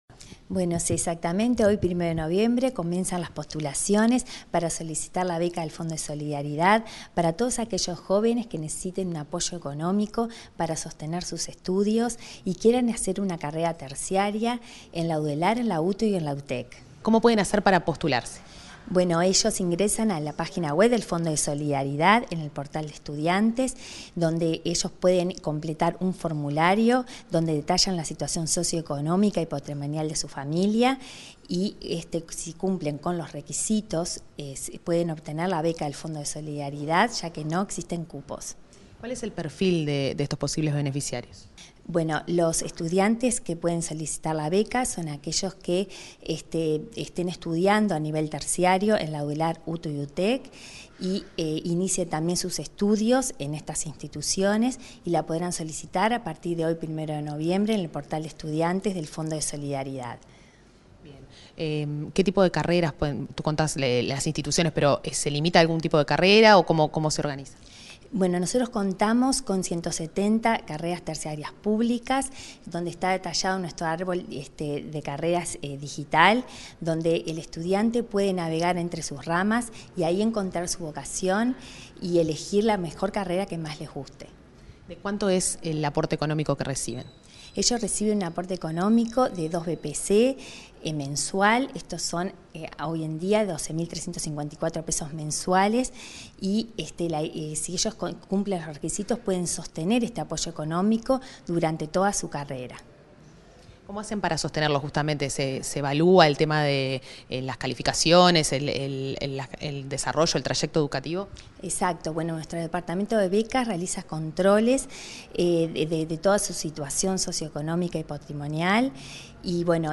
Entrevista a la presidenta del Fondo de Solidaridad, Rosario Cerviño